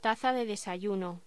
Locución: Taza de desayuno